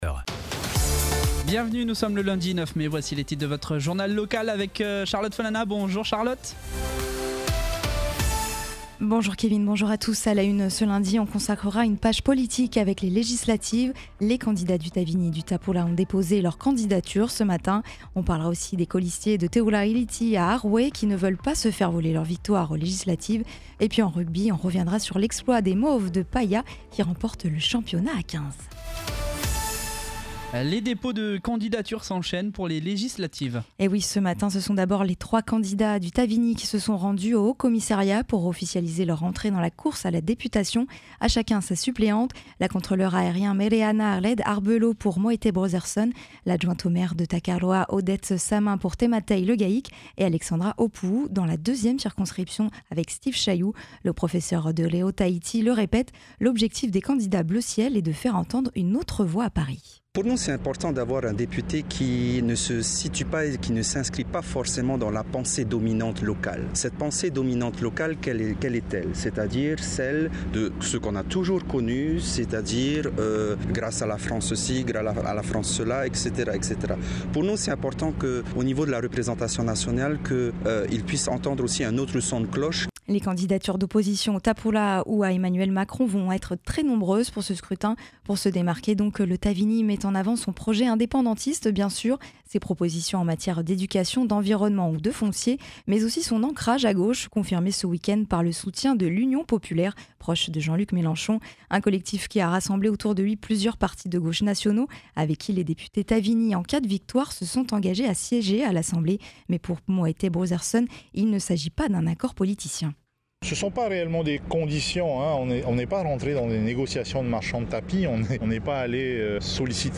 Journal de 12h, le 09/05/22